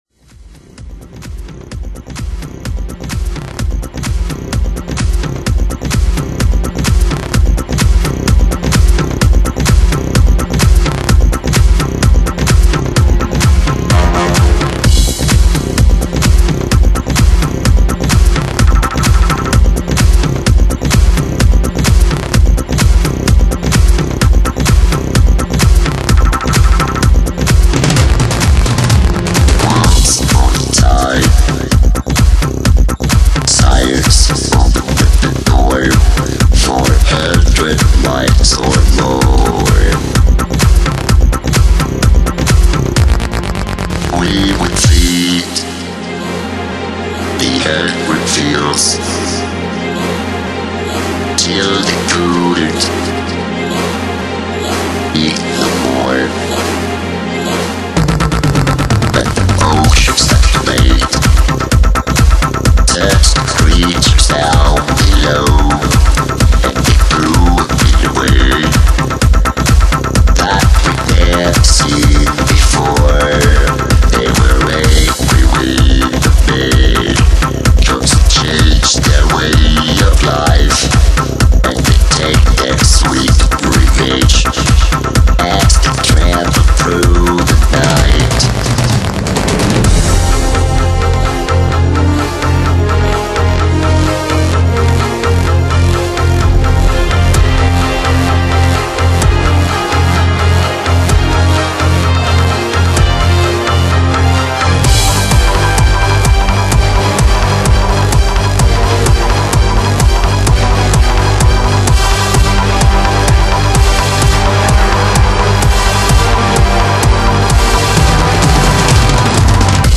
试听 五分钟选段wma/80kb
Electronic | Bootleg | 43:41 min